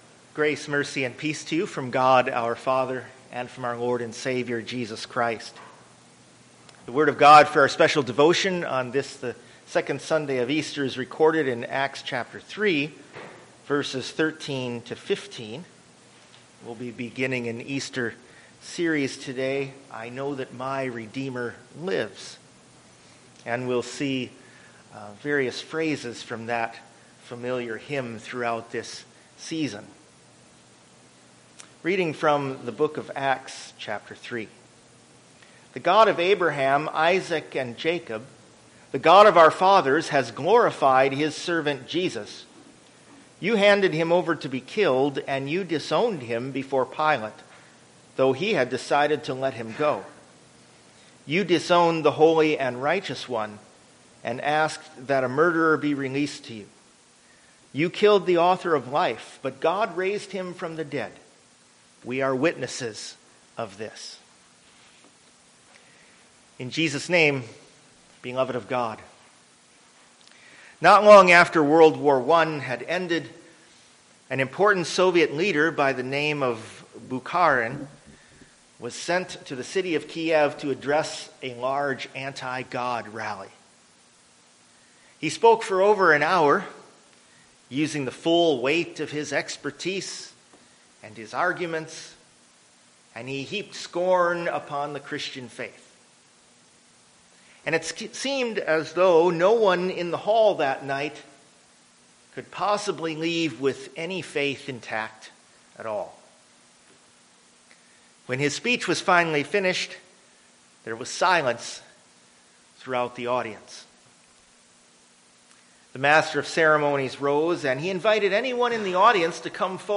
Service Type: Easter